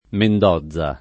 vai all'elenco alfabetico delle voci ingrandisci il carattere 100% rimpicciolisci il carattere stampa invia tramite posta elettronica codividi su Facebook Mendoza [sp. mend 1T a ] top. e cogn. — come top., anche con pn. italianizz. [ mend 0zz a ]